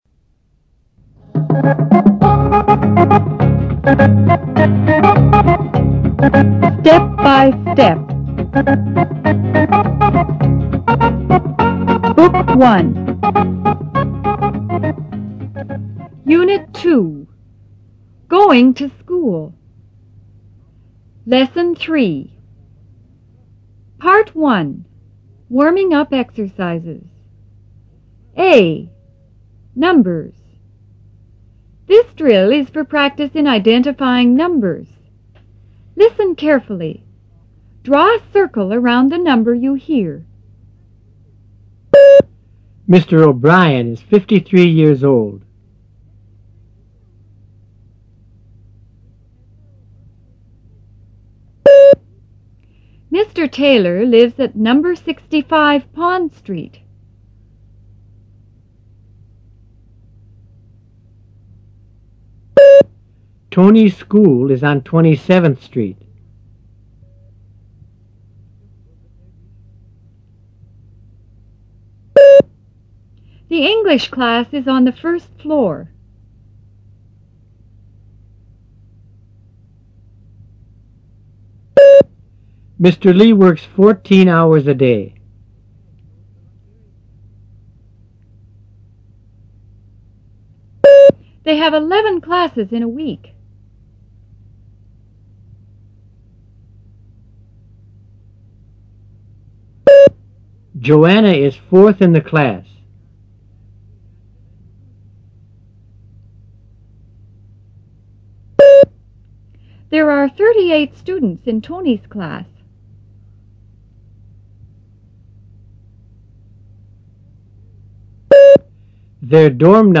B.Dictation